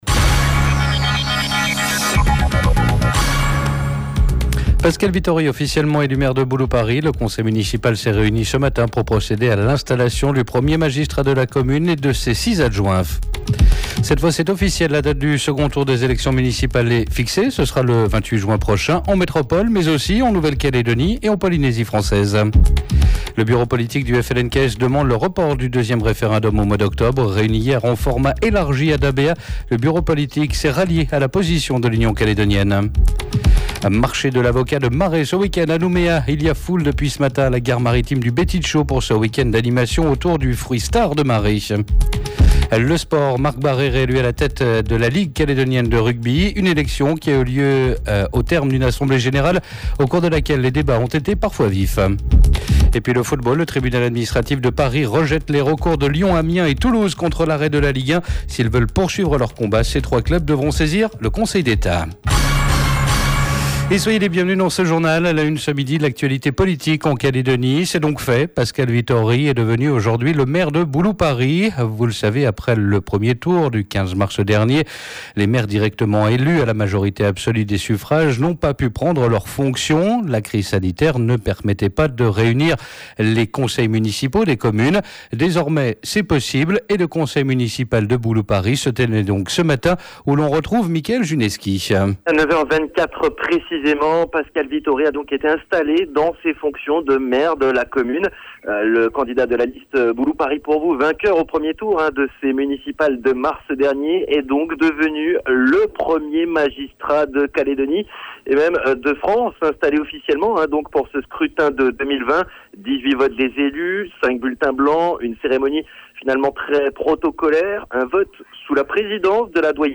JOURNAL : 23/05/20 (MIDI)